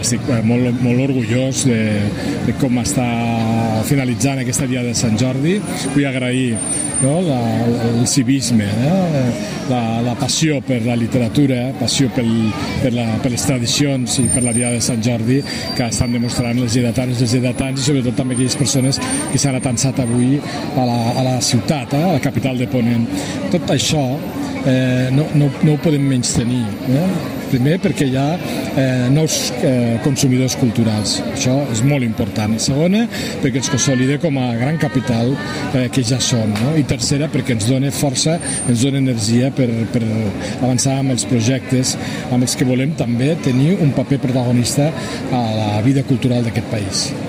Tall de veu F.Larrosa